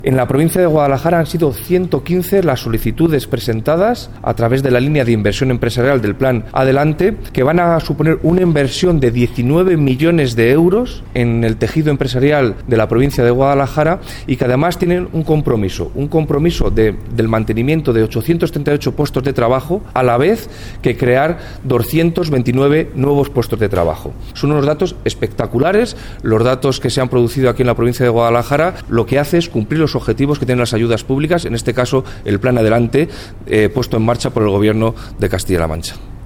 El delegado de la Junta en Guadalajara habla de las solicitudes presentadas a la última convocatoria de la línea de inversión del Plan Adelante.